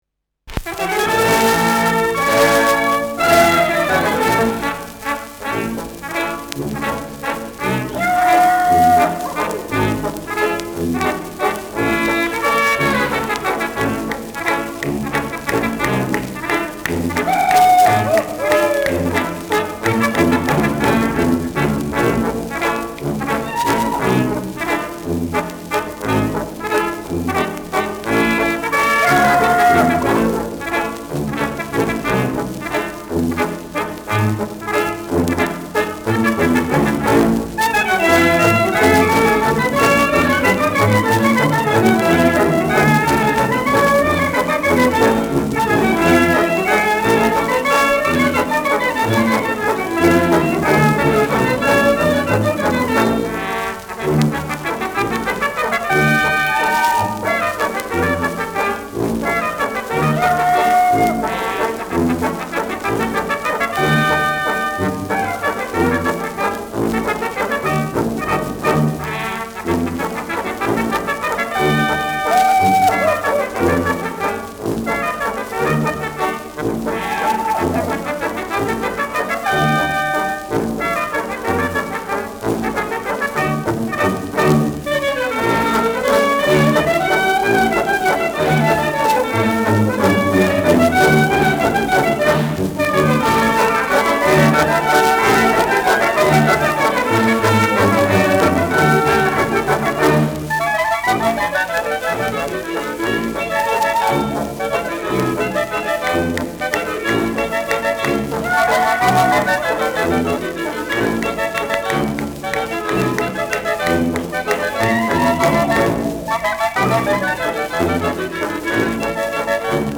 Schellackplatte
leichtes Rauschen : leichtes Knistern
Mit Juchzern und Klopfgeräuschen.
[Berlin] (Aufnahmeort)